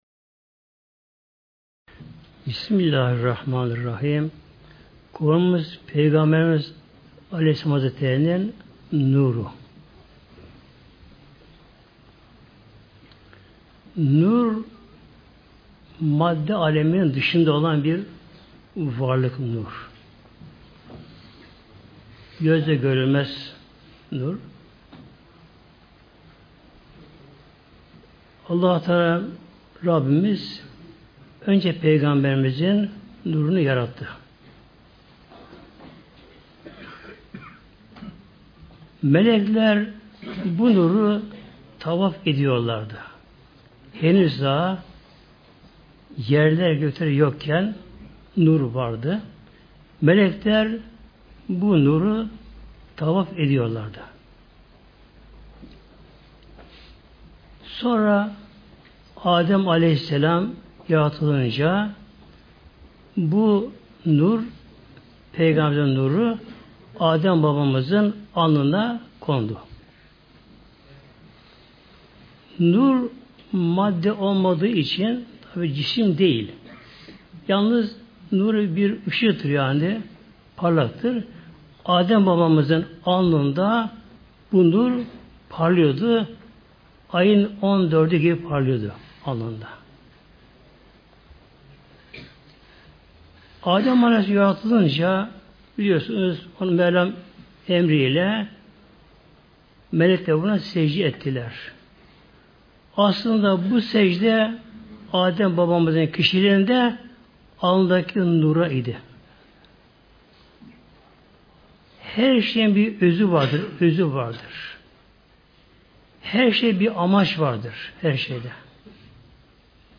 Sesli sohbeti indirmek için tıklayın (veya Sağ tıklayıp bağlantıyı farklı kaydet seçiniz)